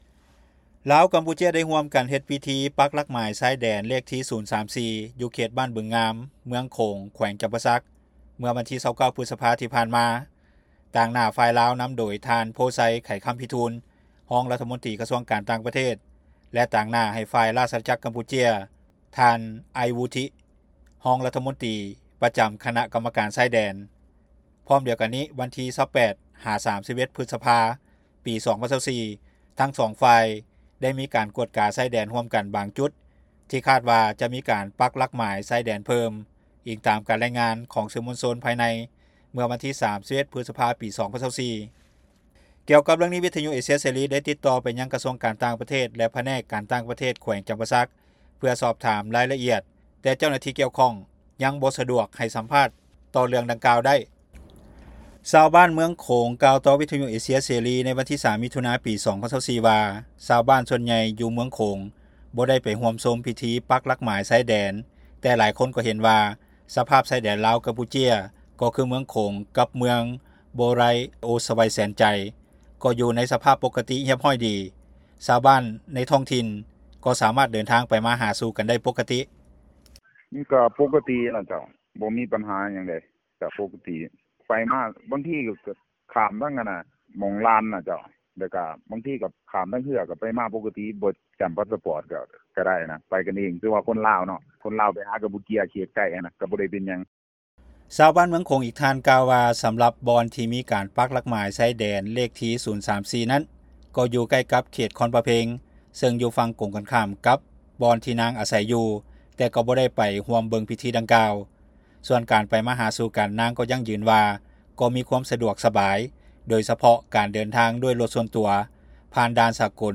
ຊາວບ້ານ ຢູ່ເມືອງໂຂງ ກ່າວຕໍ່ວິທຍຸເອເຊັຽເສຣີ ໃນວັນທີ 03 ມິຖຸນາ 2024 ວ່າ ຊາວບ້ານສ່ວນໃຫຍ່ຢູ່ເມືອງໂຂງ ບໍ່ໄດ້ໄປຮ່ວມຊົມພິທີປັກຫຼັກໝາຍ ຊາຍແດນ ແຕ່ຫຼາຍຄົນກໍ່ເຫັນວ່າ ສະພາບຊາຍແດນລາວ-ກຳປູເຈຍ ກໍ່ຄືເມືອງໂຂງ ກັບເມືອງໂບໄຣໂອສະໄວ ແສນໃຈ ກໍ່ຢູ່ໃນສະພາບປົກກະຕິ ຮຽບຮ້ອຍດີ.